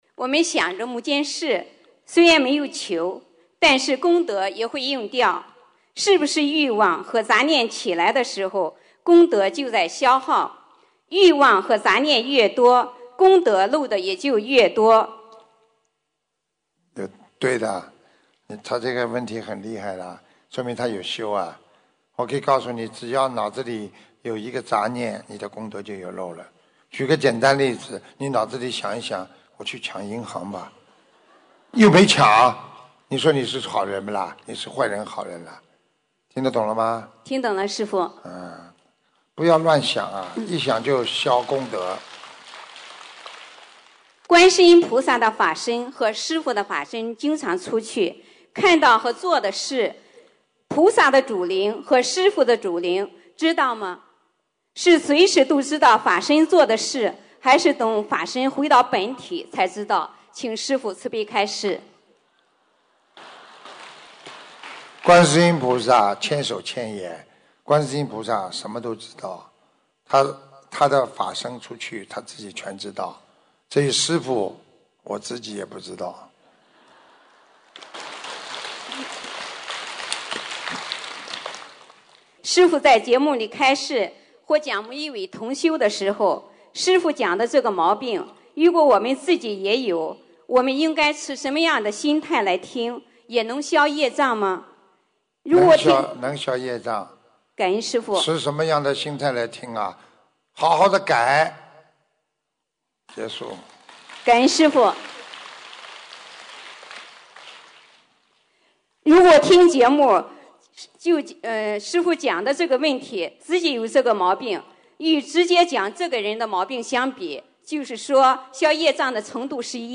吉隆坡世界佛友见面会共修组提问161226